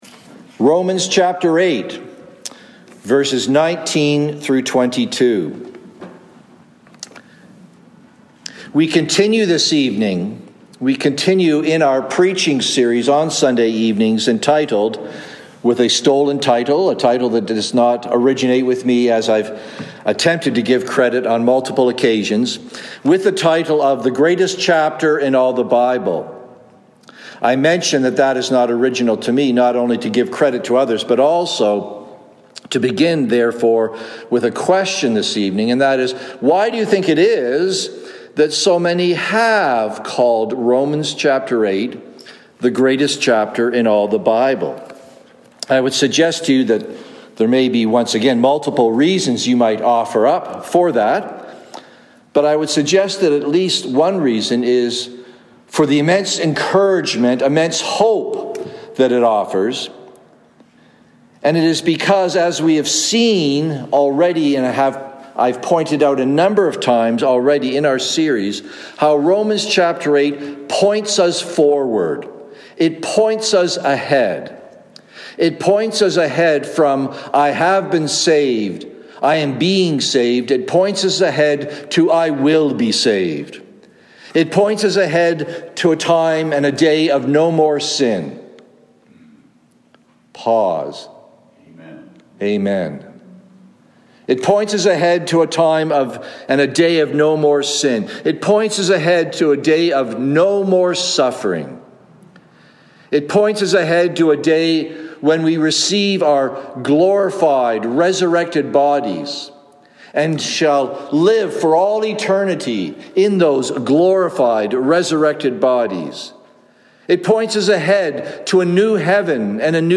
Sermons | Cranbrook Fellowship Baptist
"Creation Groans" (Evening Service)